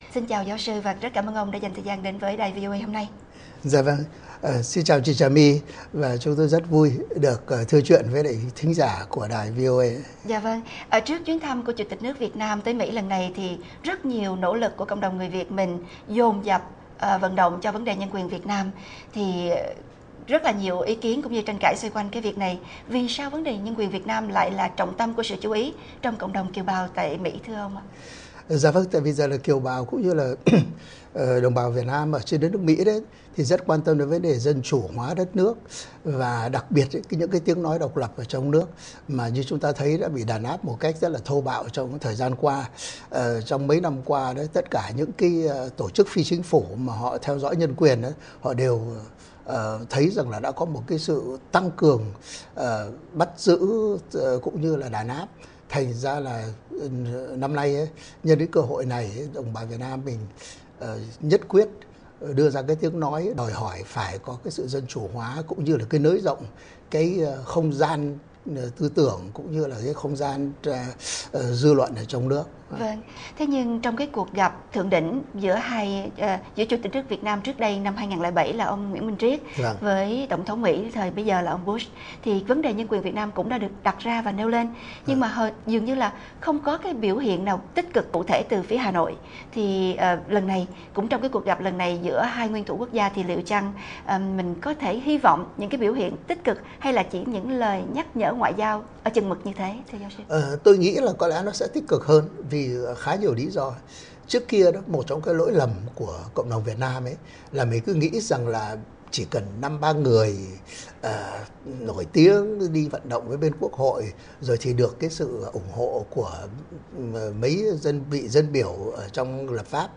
Xin mời quý vị theo dõi cuộc trao đổi giữa đài VOA và giáo sư, nhà báo, nhà khảo cứu